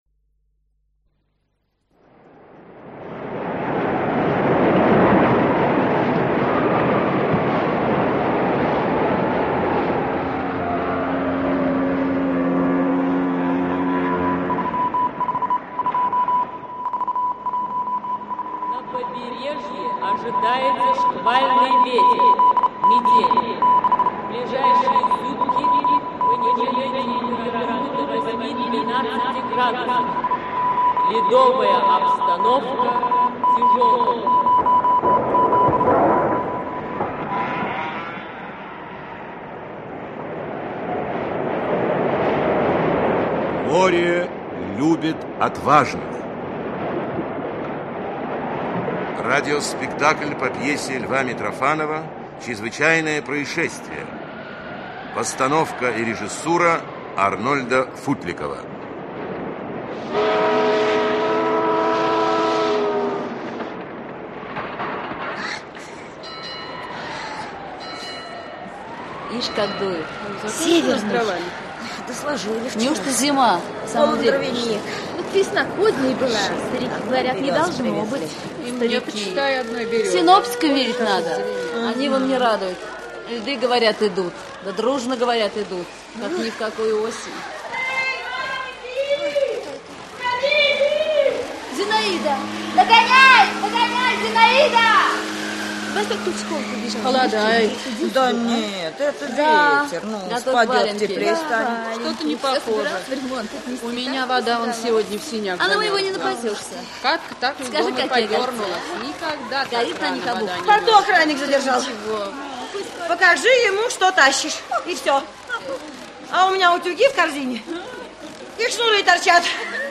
Аудиокнига Море любит отважных | Библиотека аудиокниг
Aудиокнига Море любит отважных Автор Лев Иванович Митрофанов Читает аудиокнигу Актерский коллектив.